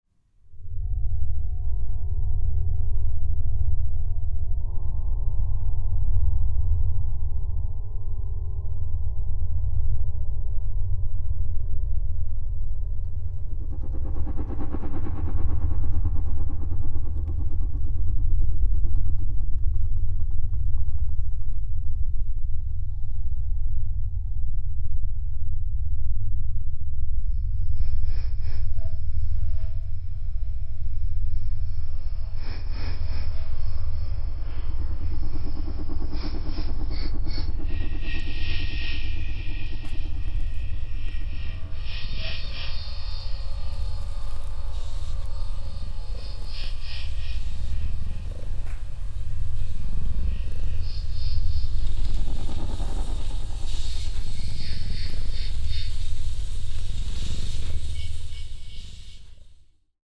Accordion